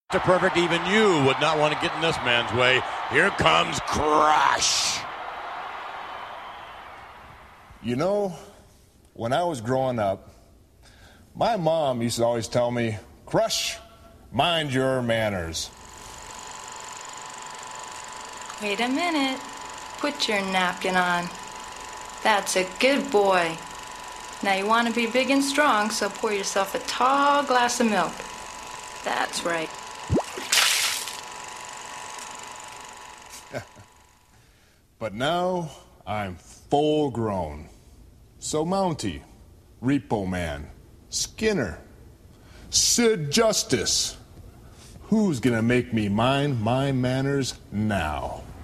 His “ha ha ha” after every dumb thing he said.